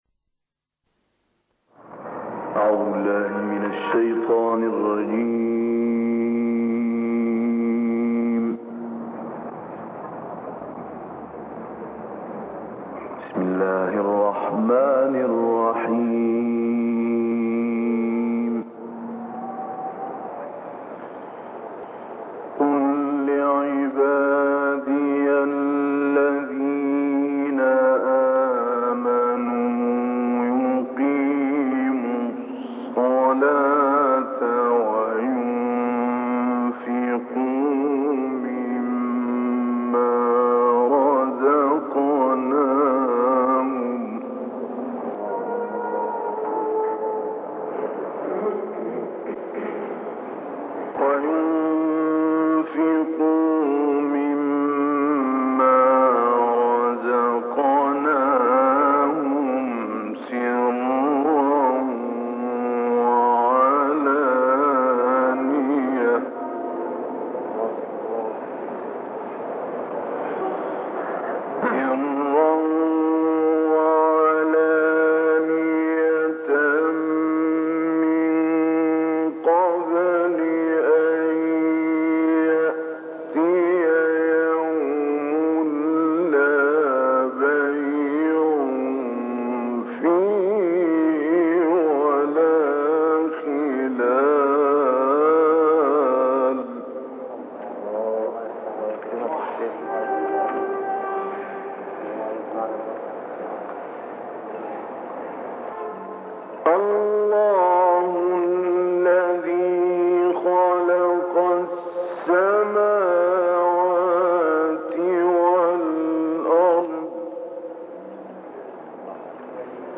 تلاوات معطرة - الكوثر: تلاوة عطرة للقارئ الشيخ محمود علي البنا مما تيسر له من سورتي إبراهيم (31-52) والنازعات (27-41).